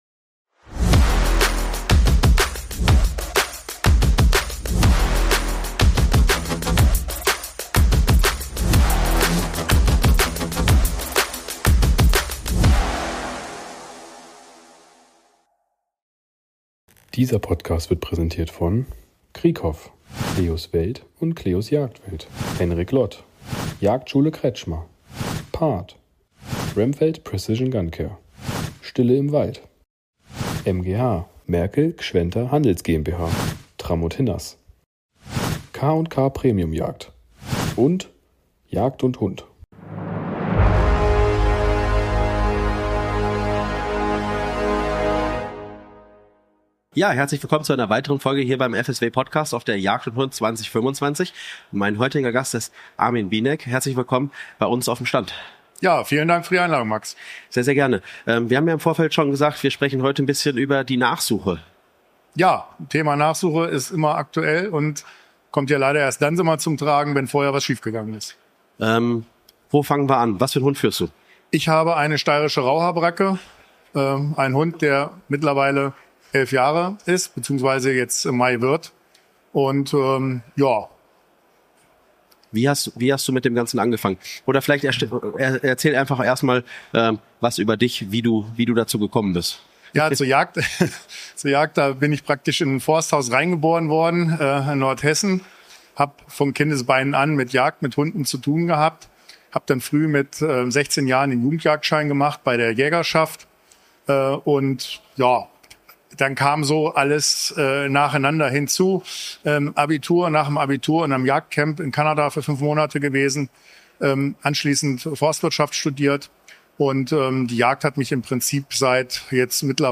Wir sind live auf Europas größter Jagdmesse unterwegs und sprechen mit spannenden Gästen aus der Jagdszene. Ob Experten, Hersteller oder passionierte Jäger – in unseren Interviews gibt’s exklusive Einblicke, spannende Neuheiten und echte Insider-Talks rund um die...